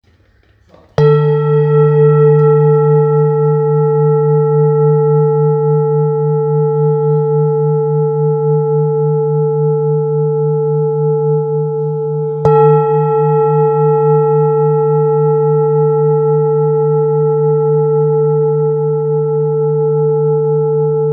Singing Bowl, Buddhist Hand Beaten, with Fine Etching Carving of Manjushree, Select Accessories
Material Seven Bronze Metal